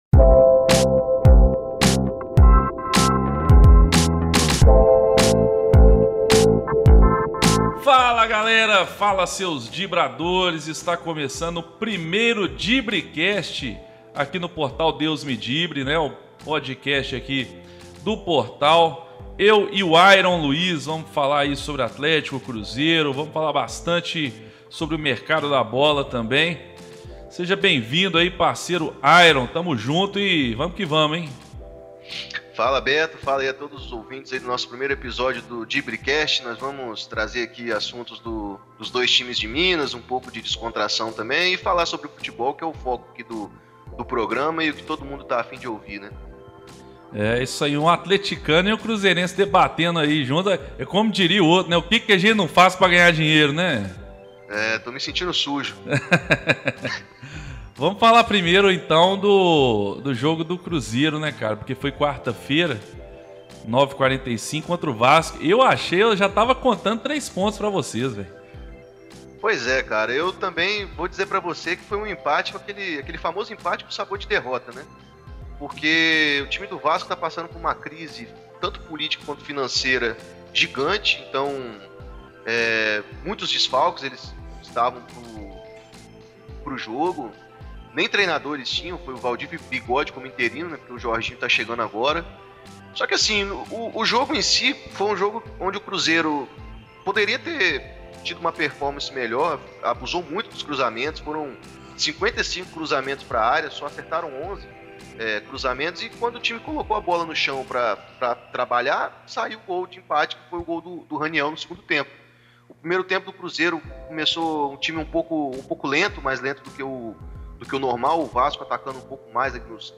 Uma resenha completamente informal para falarmos sobre os dois rivais de Minas Gerais, porém sem rivalizar, nada de alfinetadas, zoeira, nada disso, o papo é sério, mas também muito bem-humorado.